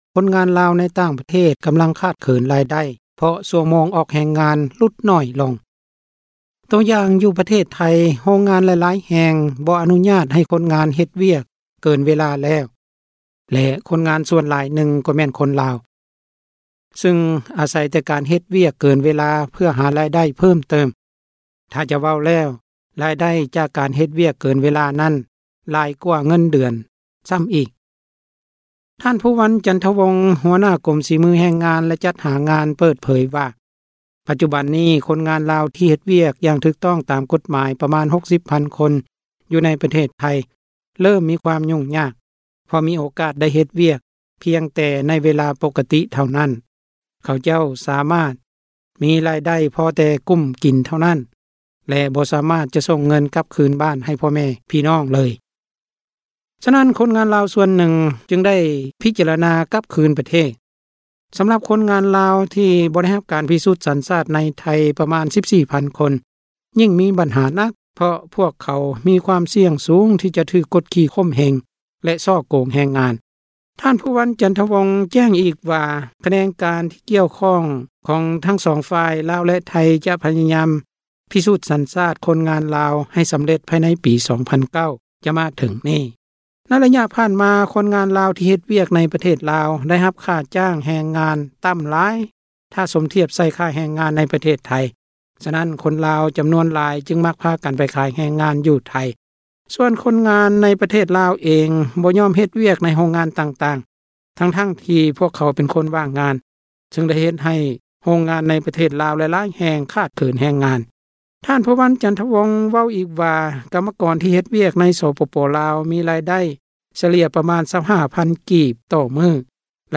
ຣາຍງາຍ